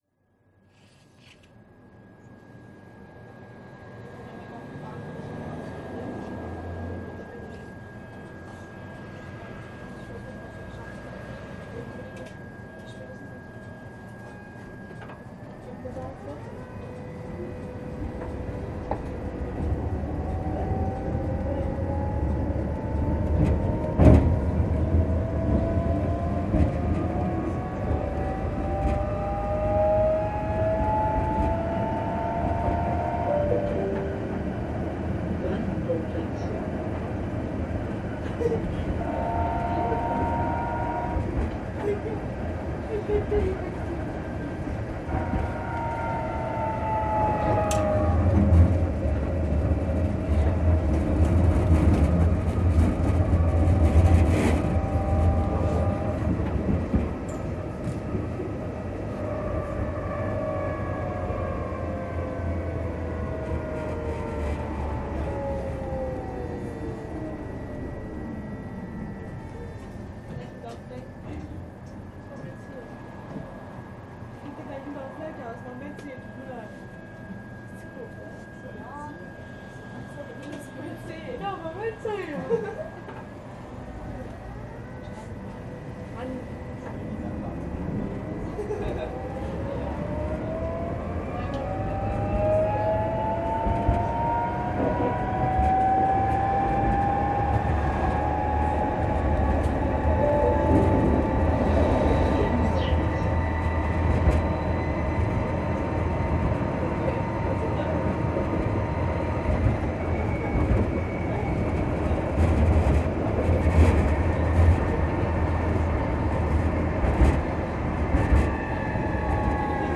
[Sound] Tatra KT4D , Line 61 Bellvuestraße -> Bahnhofstraße/Lindenstraße
ベルリン市電の一時代を代表した車両、タトラKT4Dの走行音です。
ここでお聞きいただけるのは足回り改造後の車両で、チョッパ制御の「プー」という動作音と、「ムォーン」という独特のモーター音を発します。
収録はベルリン東部の郊外を走る61系統で行い、そのうち5分ほどの区間の録音です。
The sound from under the floor was different from original.
This sound was recorded from Bellvuestraße to Bahnhofstraße/Lindenstraße on route 61.